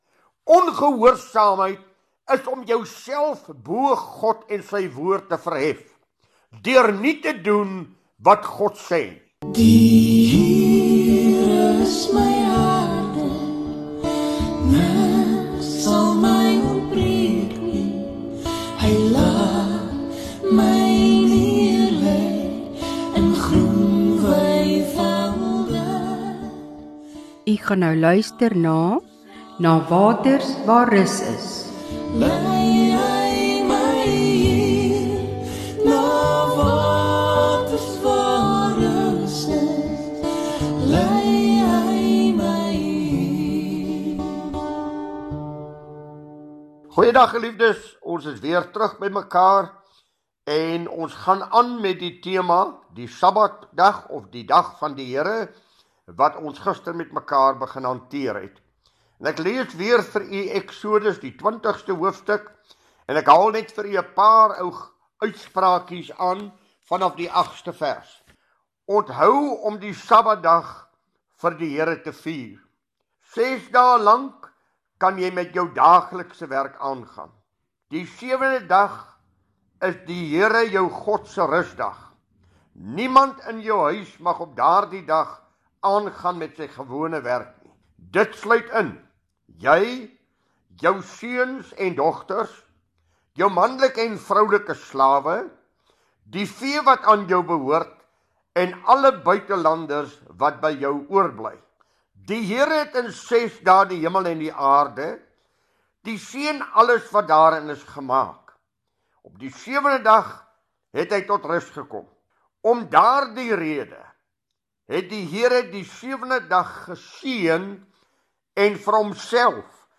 DIE PREDIKER GAAN VOORT OOR DIE GEBRUIK/MISBRUIK VAN DIE SAMMAT, VERNAAM OOR DIE DAG VAN DIE HERE.